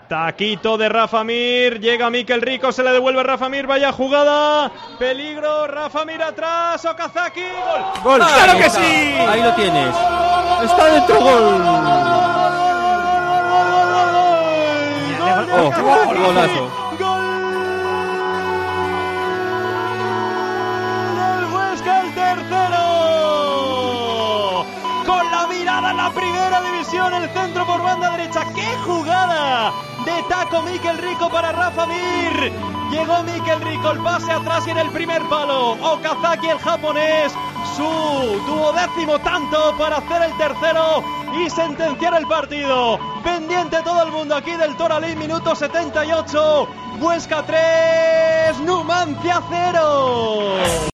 Narración Gol de Okazaki / 3-0